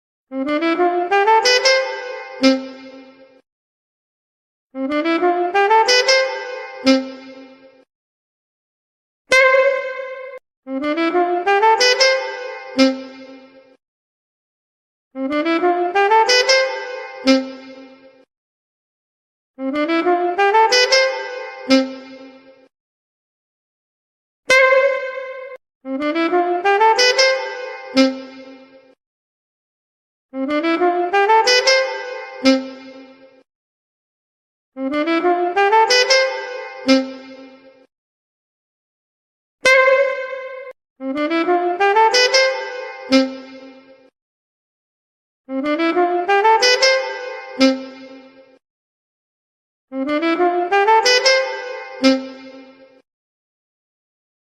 Genre: Nada dering lucu
Suaranya unik, lucu, dan khas Indonesia banget!